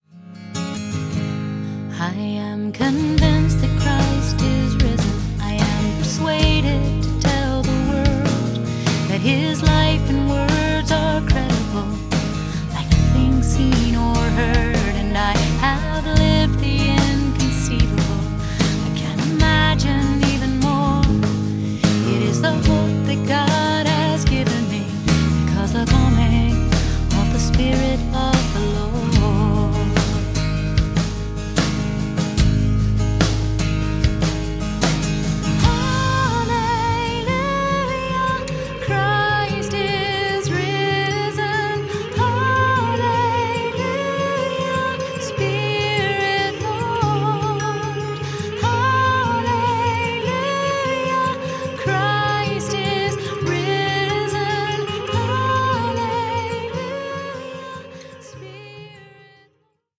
Verpackt in leichtfüßige Popmusik mit Celtic-Folk-Einflüssen